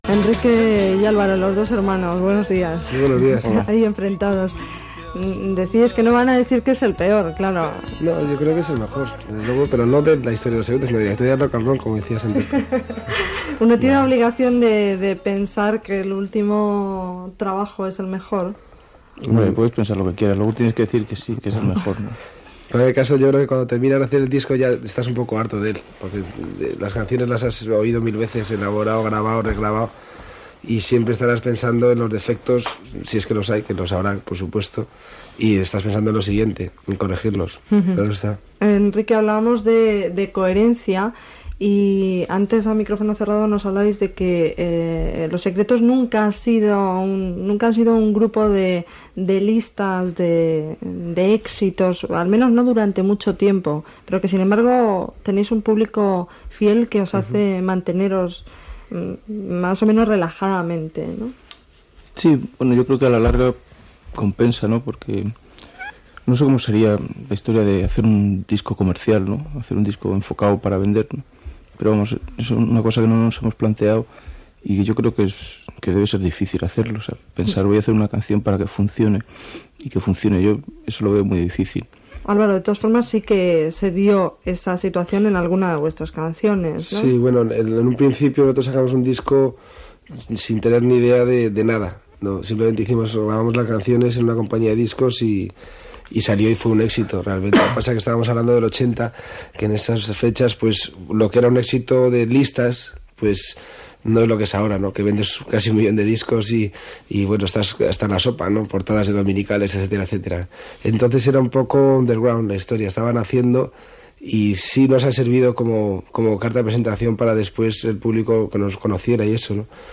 Entrevista a Enrique Urquijo i Álvaro Urquijo del grup Los Secretos que han publicat l'àlbum "La calle del olvido"
Entreteniment